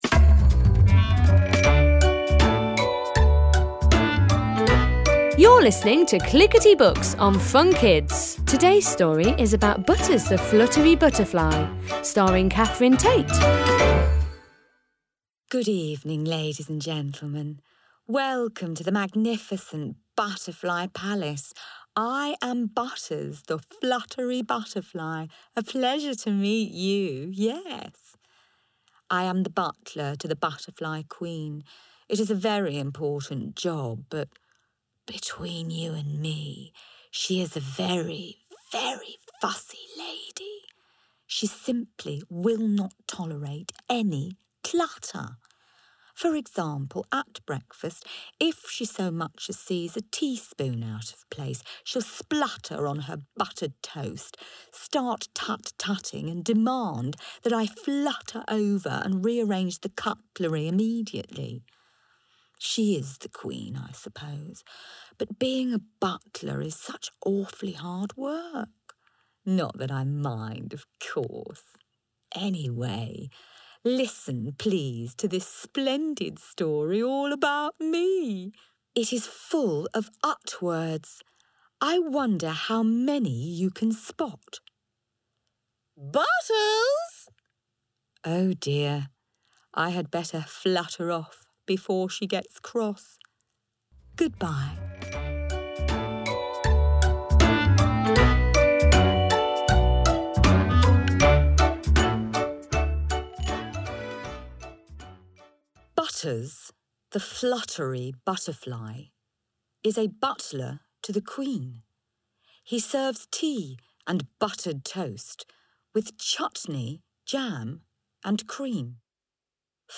Audio CD included with the stories read by Catherine Tate.